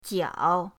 jiao3.mp3